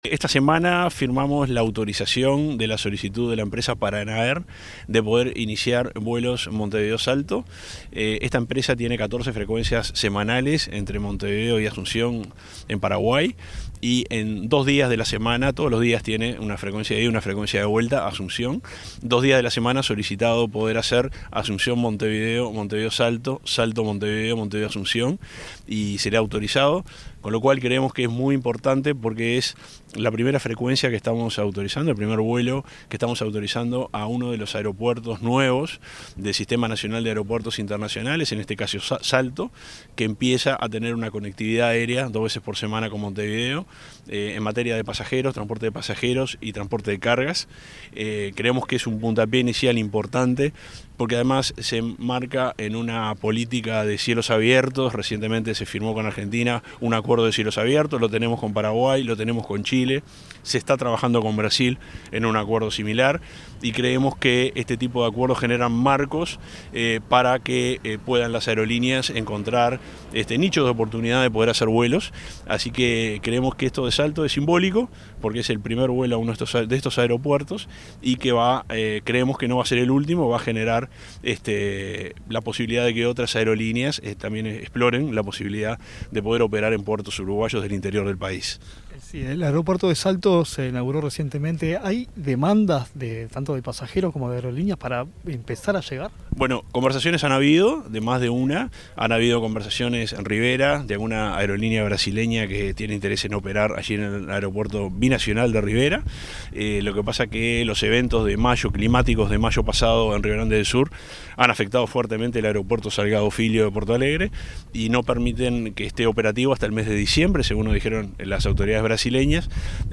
Entrevista al subsecretario de Transporte, Juan José Olaizola